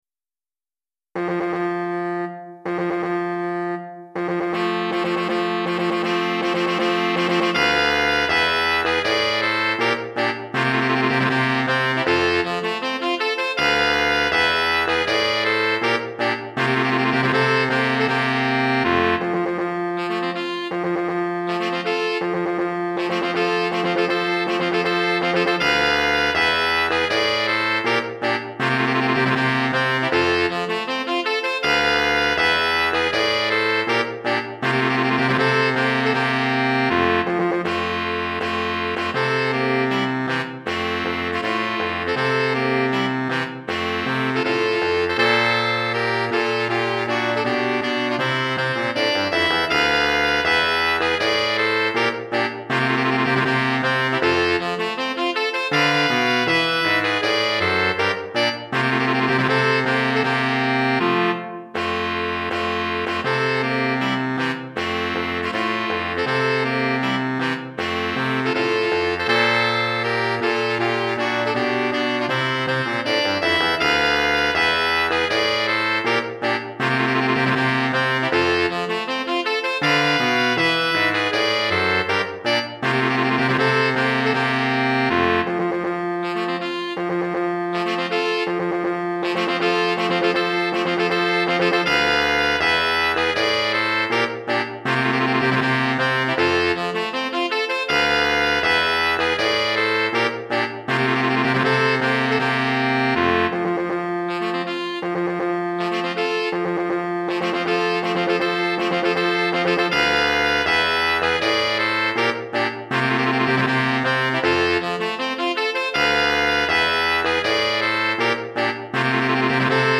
3 Saxophones